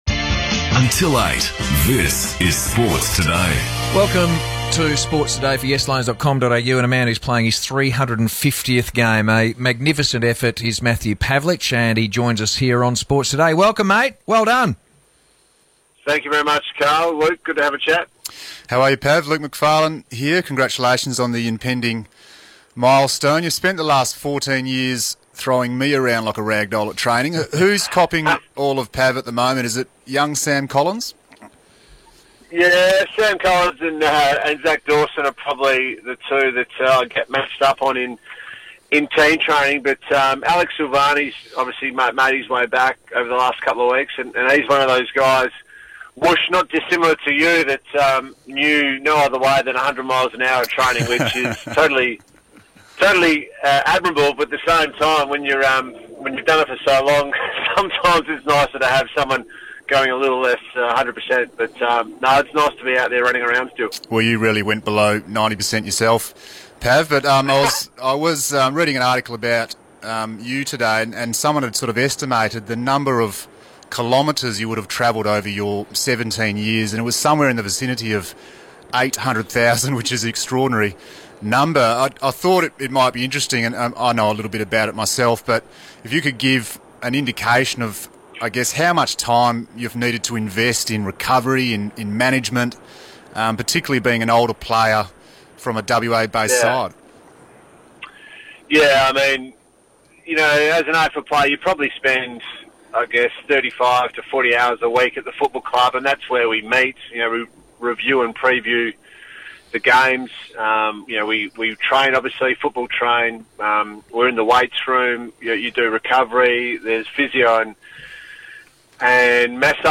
Matthew Pavlich talks to Luke McPharlin and Karl Langdon ahead of his 350th game.